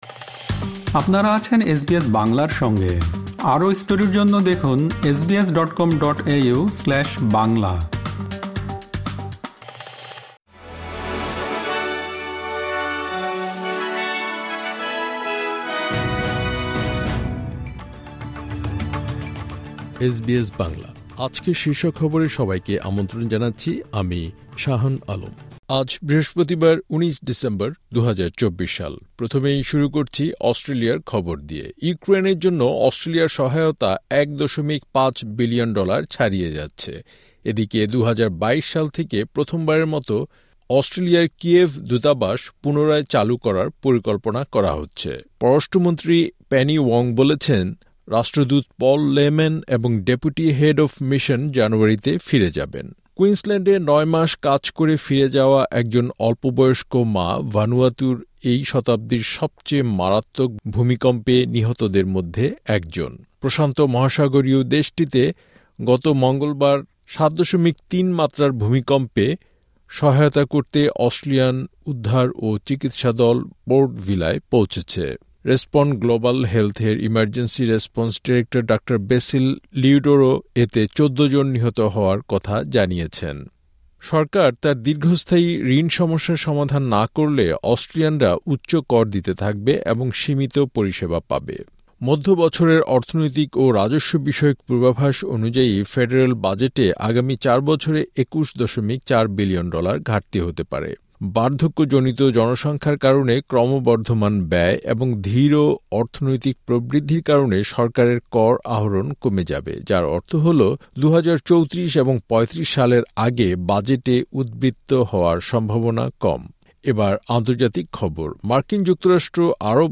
এসবিএস বাংলা শীর্ষ খবর: ১৯ ডিসেম্বর, ২০২৪